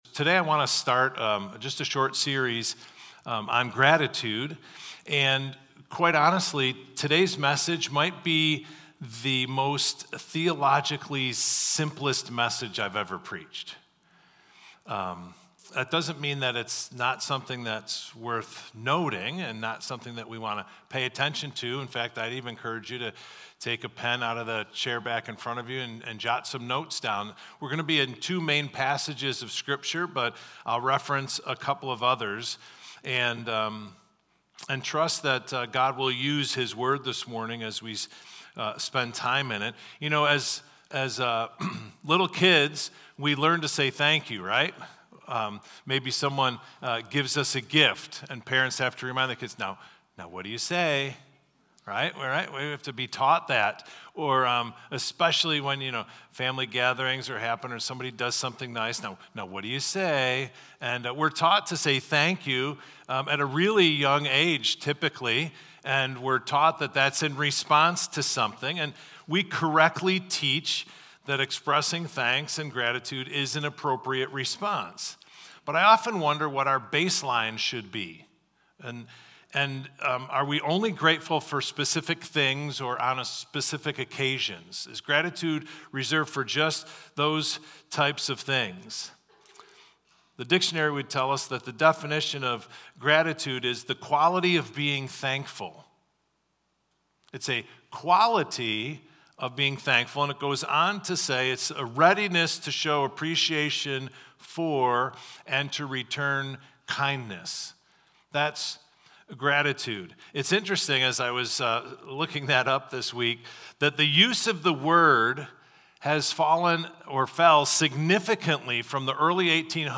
Victor Community Church Sunday Messages / Gratitude: Foundational vs. Transactional Gratitude (November, 10th 2024)